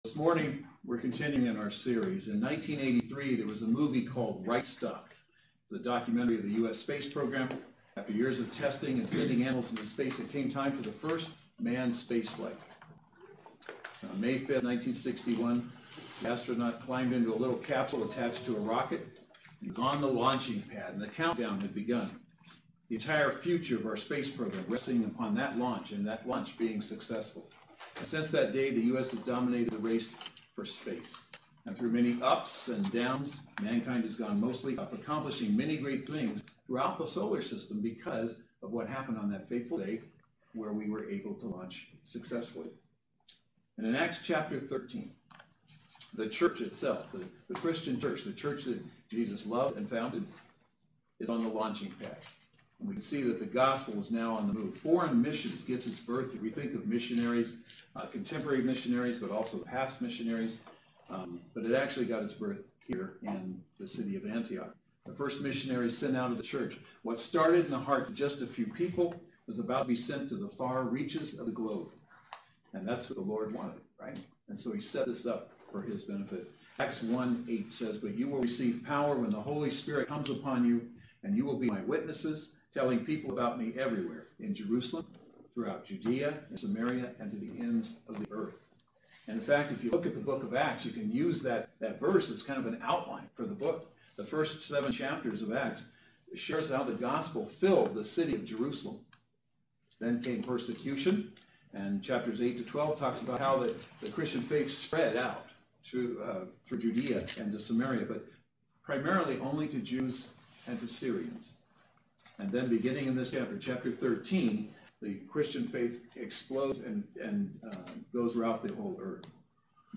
Service Type: Saturday Worship Service